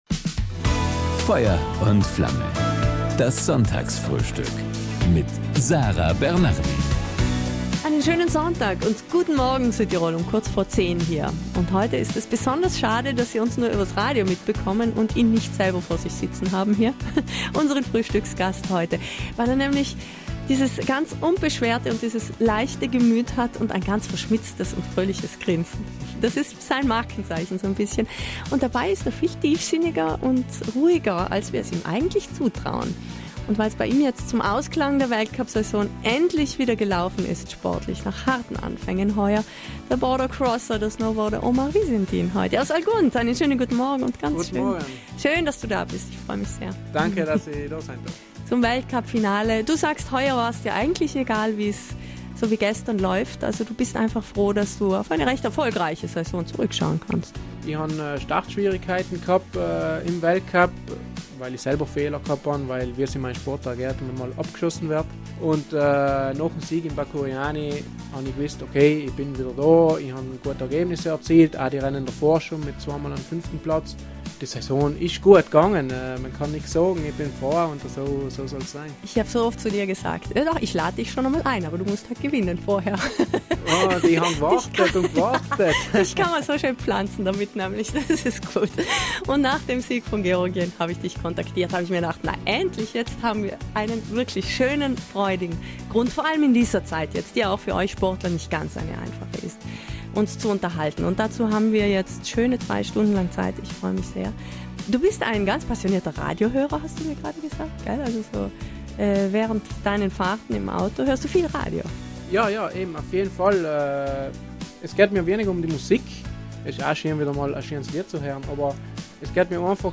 Ein Sportler voller Überraschungen und großer Ziele: Omar Visintin hat uns am Sonntag von allem ausführlich in „Feuer & Flamme“, unserem Sonntagsfrühstück, erzählt...